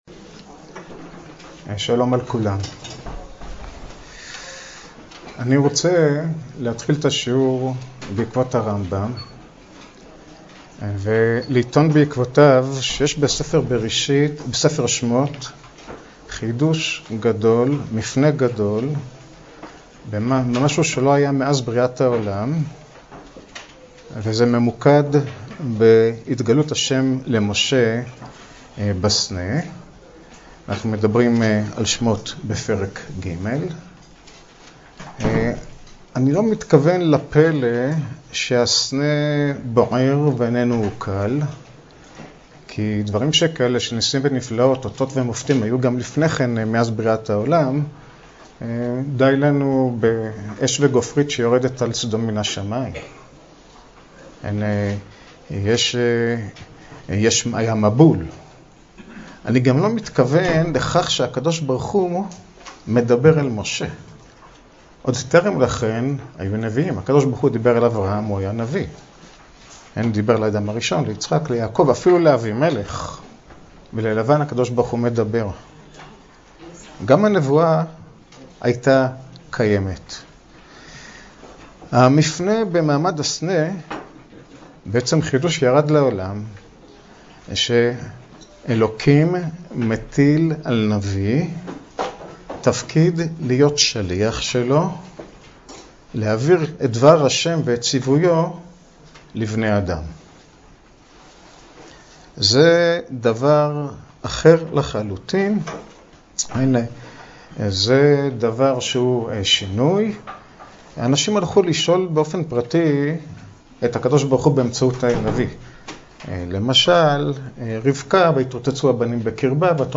השיעור באדיבות אתר התנ"ך וניתן במסגרת ימי העיון בתנ"ך של המכללה האקדמית הרצוג תשפ"ב